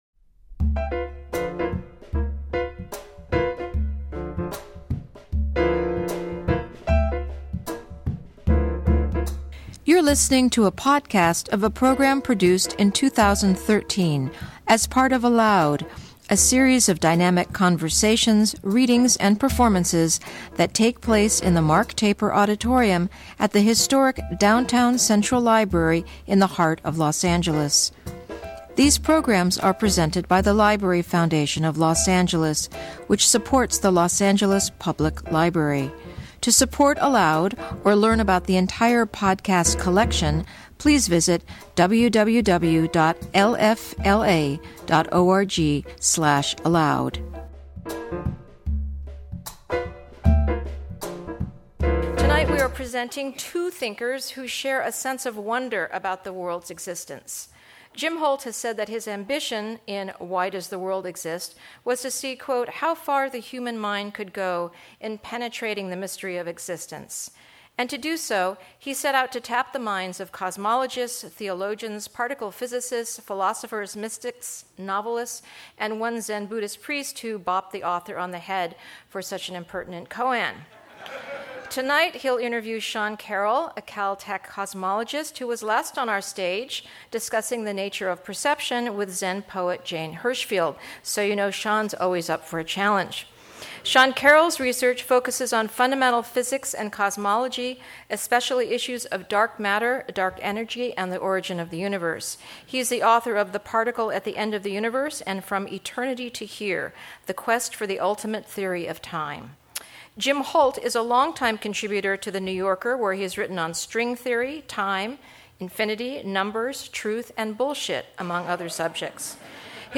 Jim Holt and Sean Carroll in Conversation